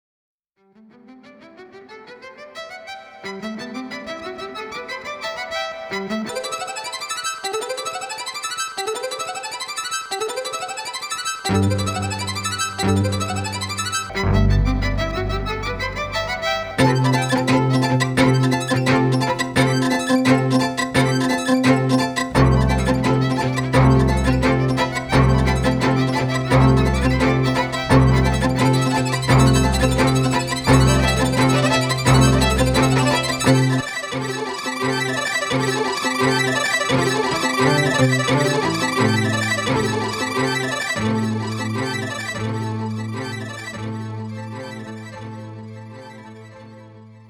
Genre: Indie Pop, Orchestral Pop